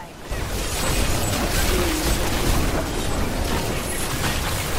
Download Sword Fighting Meme sound effect for free.
Sword Fighting Meme